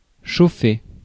Ääntäminen
Synonyymit choper Ääntäminen France: IPA: [ʃo.fe] Haettu sana löytyi näillä lähdekielillä: ranska Käännös Verbit 1. загорещявам 2. нагорещявам 3. запарвам Määritelmät Verbit Rendre chaud .